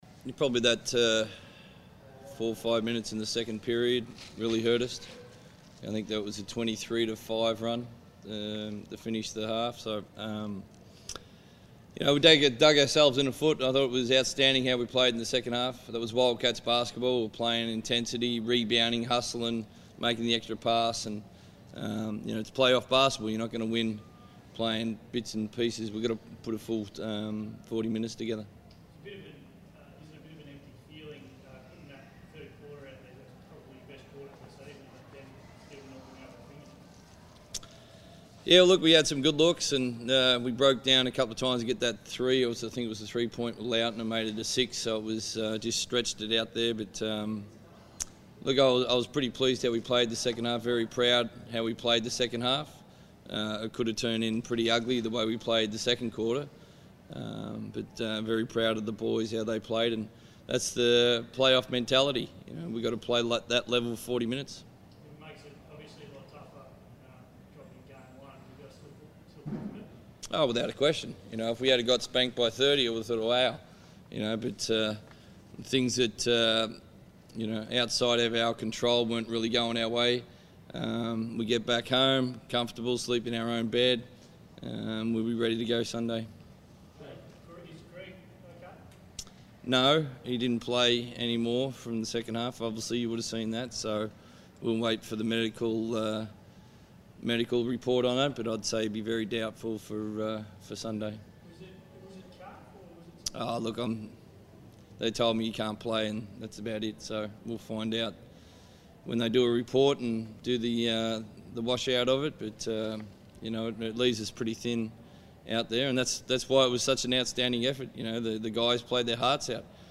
Trevor Gleeson and Damian Martin speak to the media following the Perth Wildcats 64-71 defeat to the Cairns Taipans.